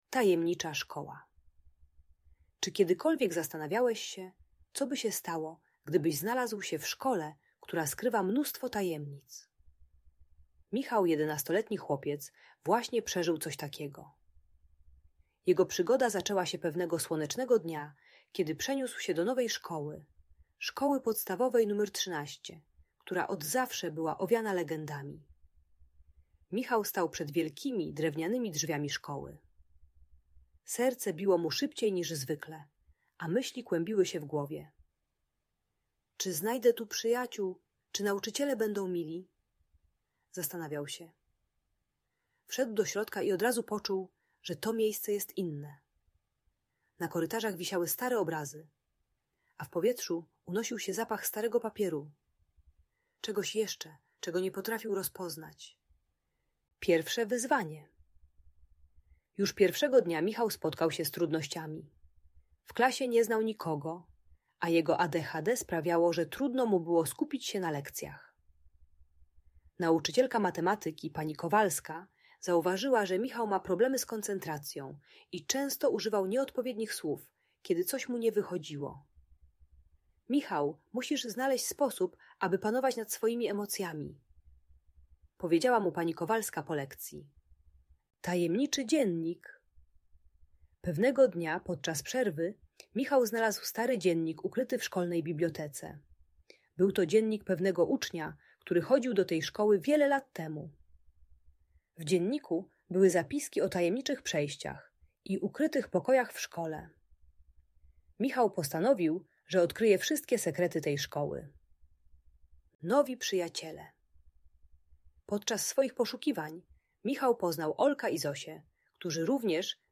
Uczy panowania nad emocjami, radzenia sobie z frustracją i budowania relacji z rówieśnikami. Audiobajka o adaptacji w nowej szkole i kontrolowaniu wybuchów złości.